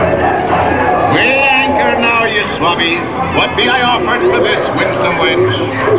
Small Clip from inside Pirates of the Carribean.